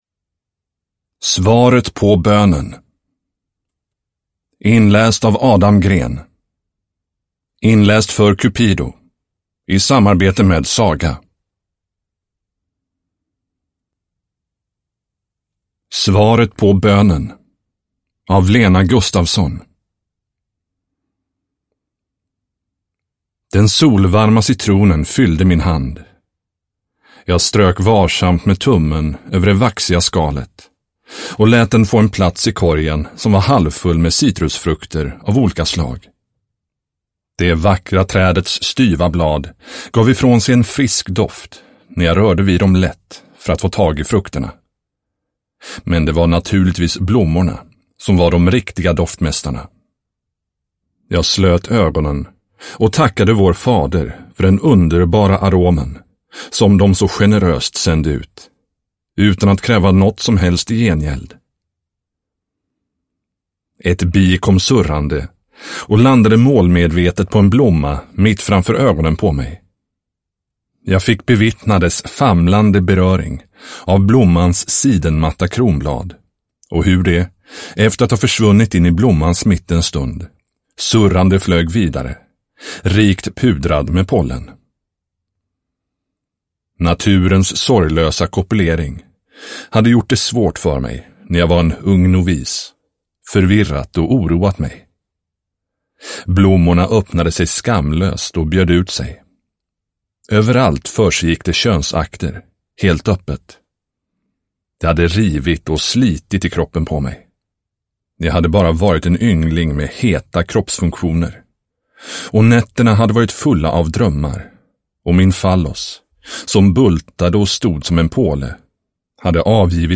Svaret på bönen (ljudbok) av Cupido